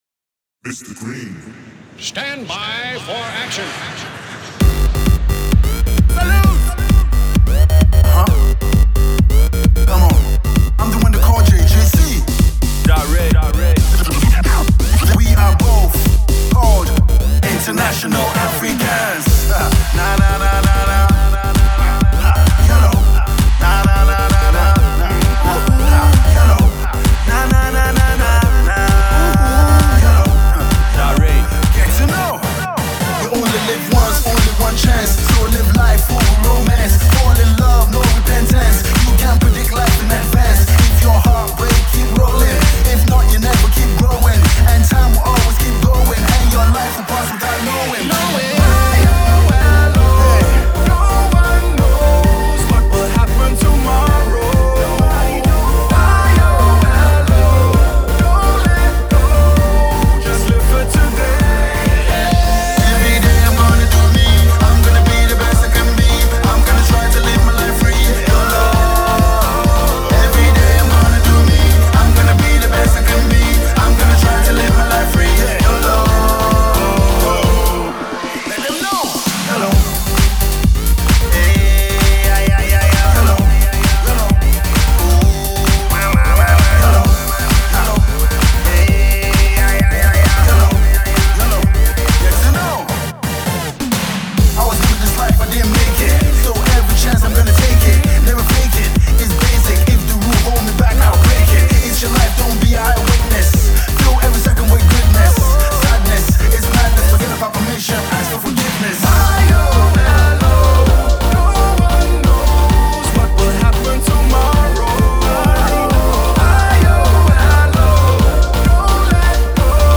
Electro fusion with Afrobeats and R&B